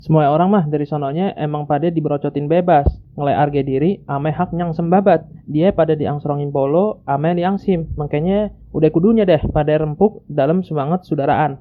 Sample text (Urban Jakartan dialect)
udhr_betawi-urbanjakartan.mp3